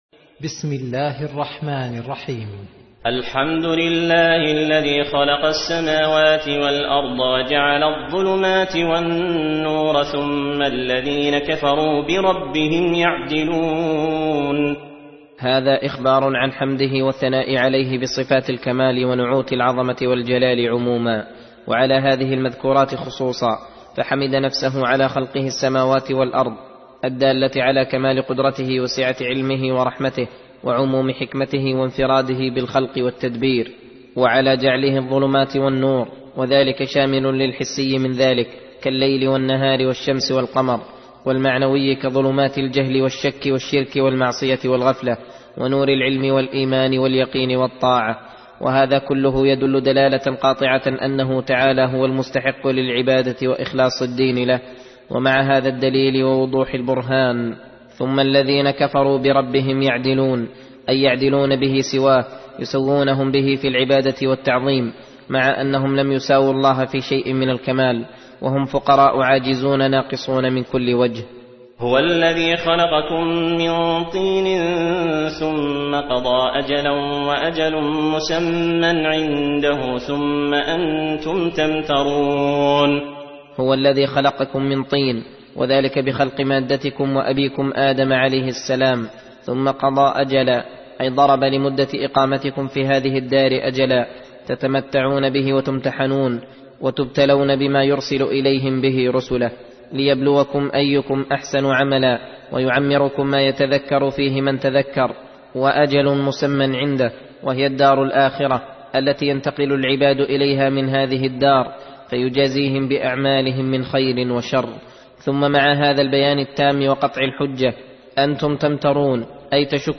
درس (25): تفسير سورة الأنعام: (1-20)